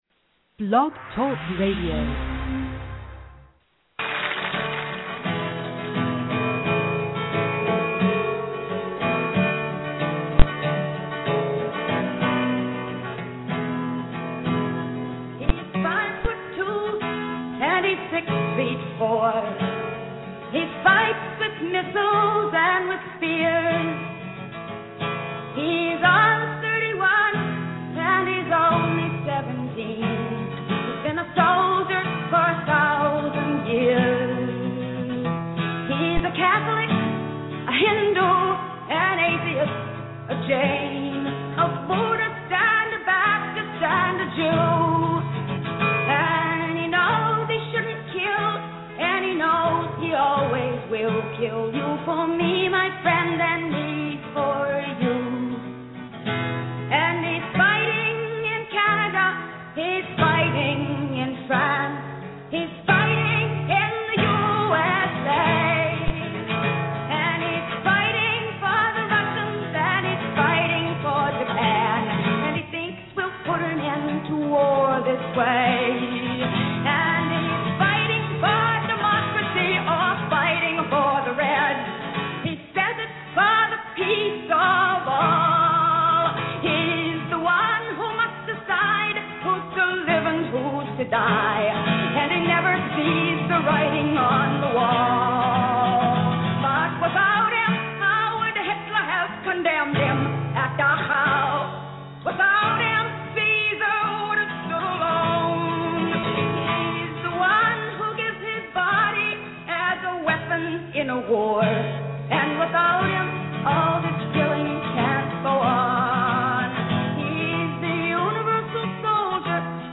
BlogTalk Radio